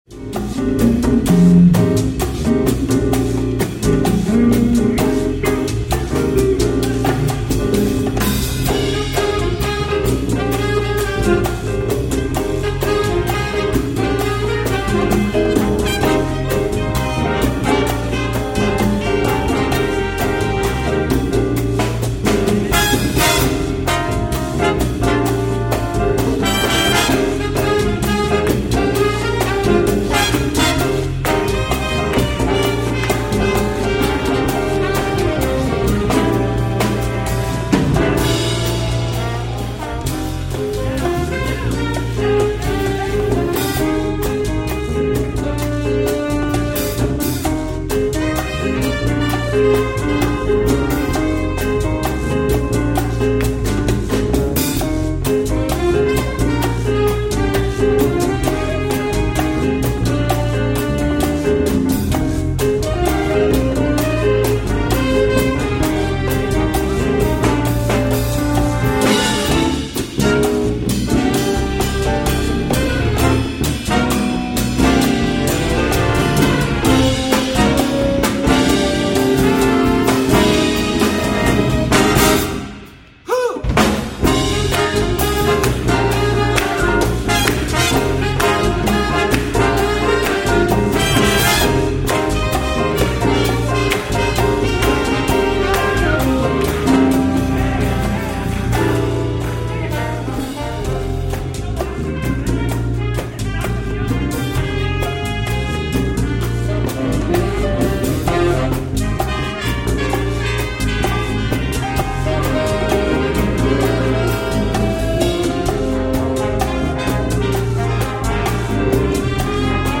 Category: big band
Style: funky cha cha
Solos: open
Instrumentation: big band (4-4-5, rhythm (4)